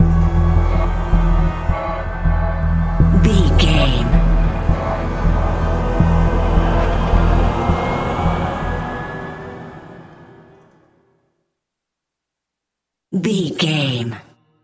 Thriller
Aeolian/Minor
E♭
Slow
synthesiser